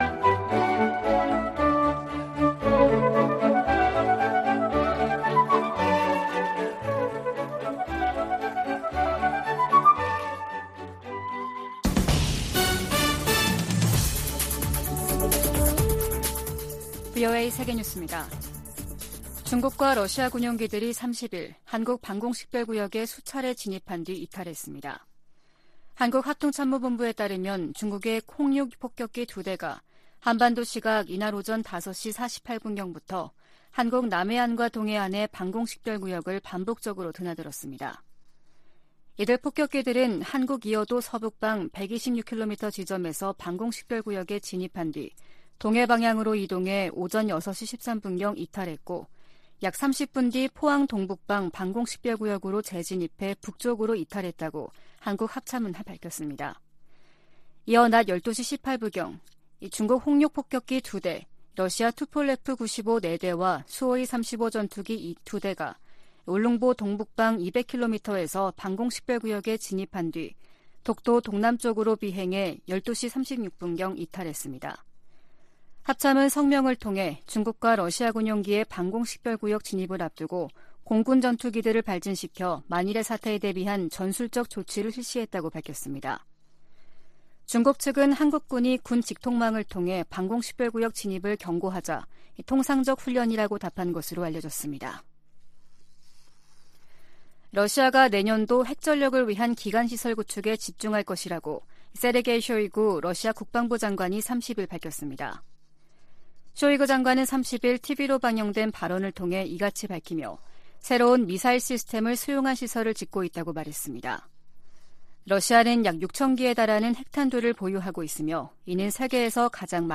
VOA 한국어 아침 뉴스 프로그램 '워싱턴 뉴스 광장' 2022년 12월 1일 방송입니다. 북한 정권이 7차 핵 실험을 강행하면 대가가 따를 것이라고 미국 국방부가 거듭 경고했습니다. 중국의 핵탄두 보유고가 2년여 만에 2배인 400개를 넘어섰으며 2035년에는 1천 500개에 이를 것이라고 미국 국방부가 밝혔습니다.